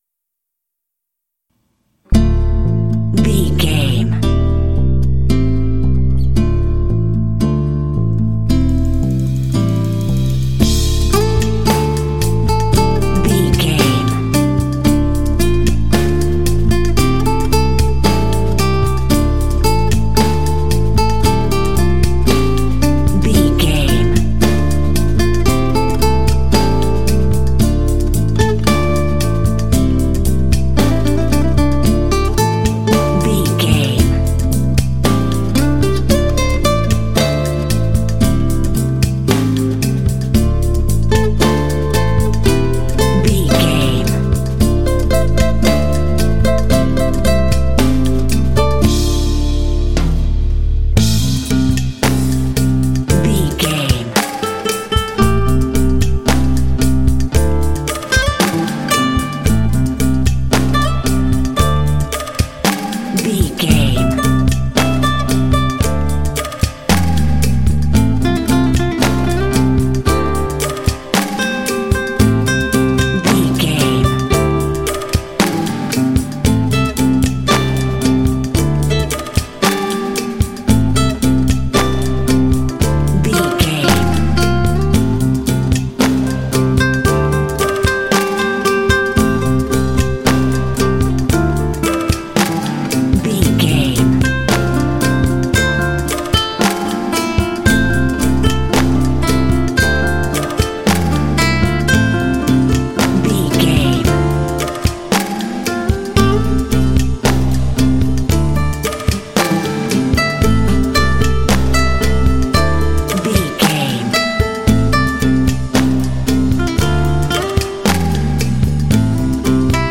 Aeolian/Minor
dramatic
foreboding
smooth
acoustic guitar
bass guitar
drums
percussion
Lounge
downtempo